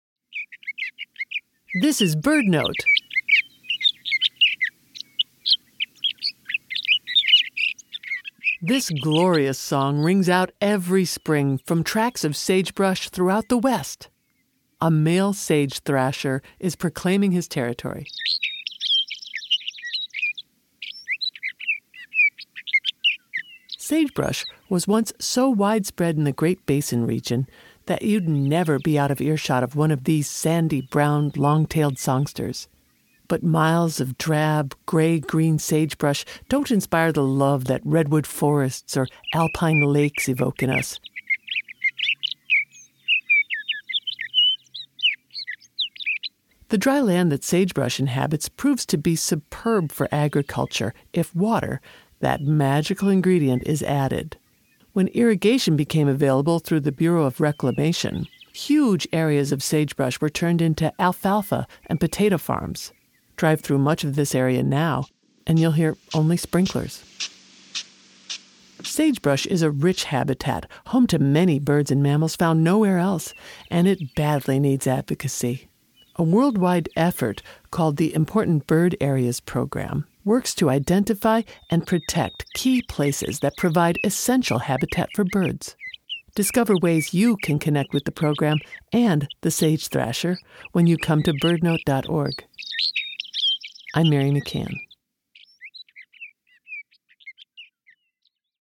The glorious song of the male Sage Thrasher rings out every spring from sagebrush throughout the West.